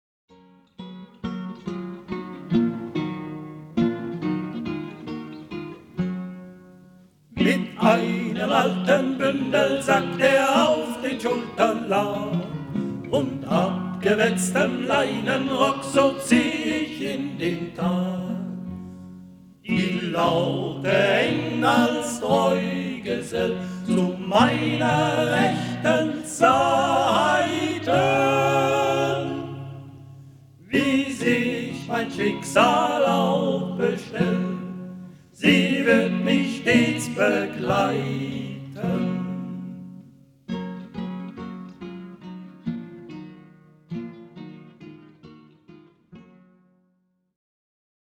Duett